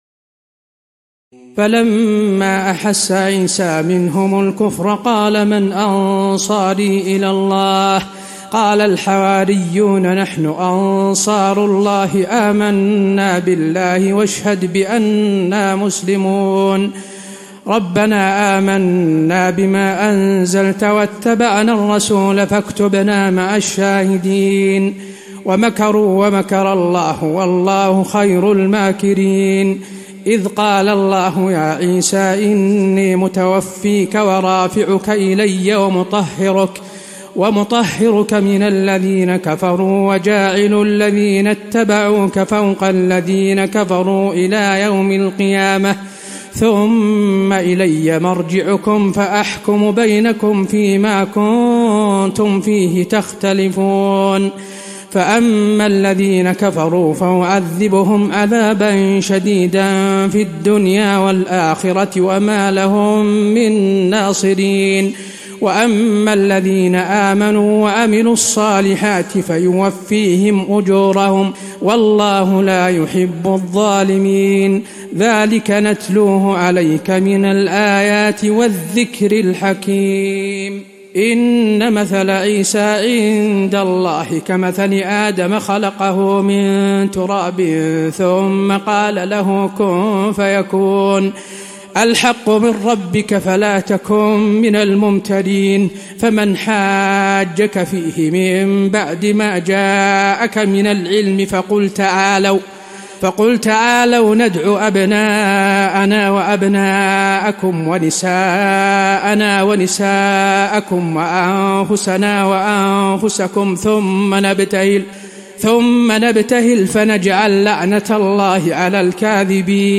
تراويح الليلة الثالثة رمضان 1435هـ من سورة آل عمران (52-132) Taraweeh 3st night Ramadan 1435H from Surah Aal-i-Imraan > تراويح الحرم النبوي عام 1435 🕌 > التراويح - تلاوات الحرمين